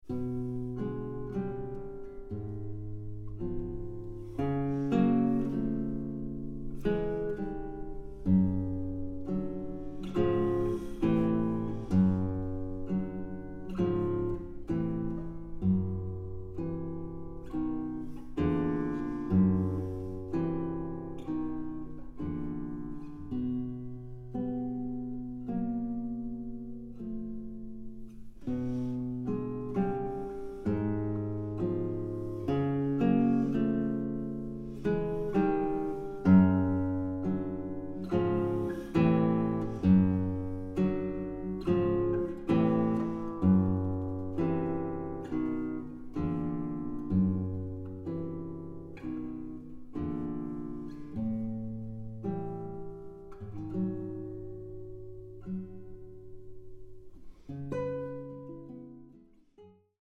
Eine musikalische Collage kanonischer Gitarrenwerke
Gitarre